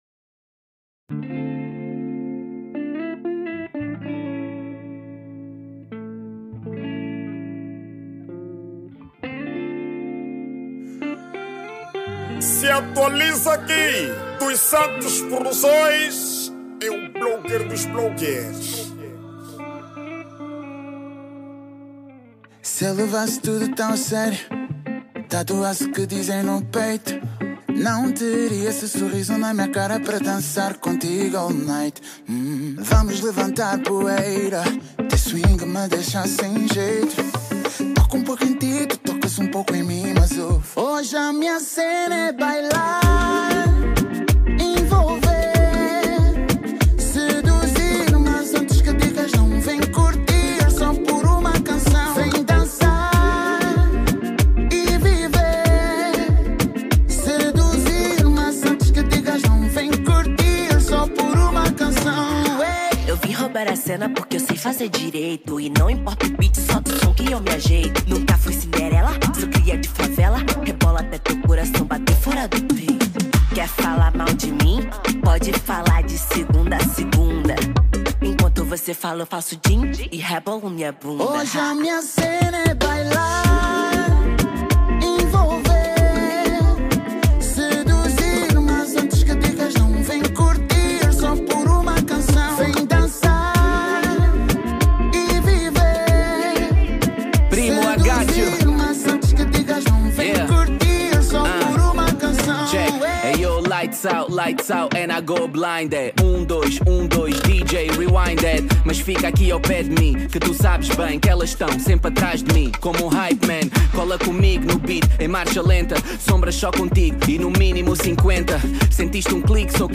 Categoria:   Pop